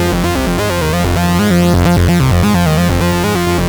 La Haine F 130.wav